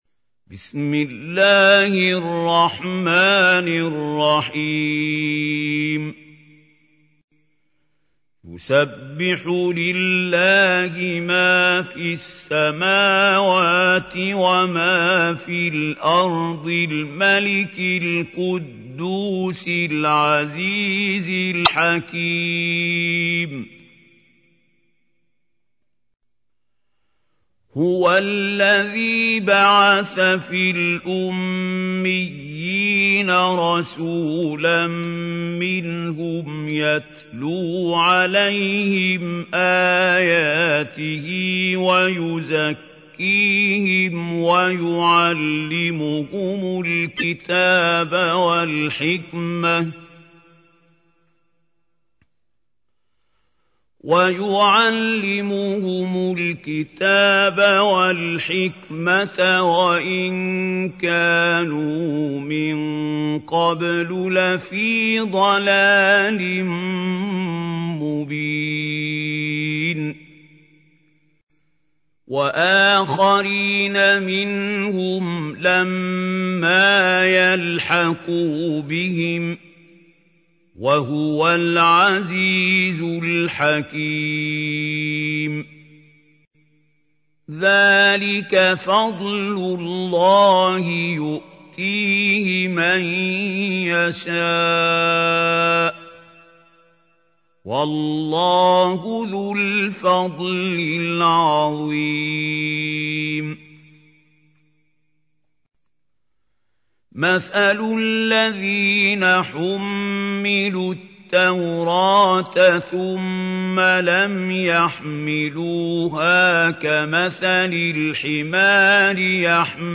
تحميل : 62. سورة الجمعة / القارئ محمود خليل الحصري / القرآن الكريم / موقع يا حسين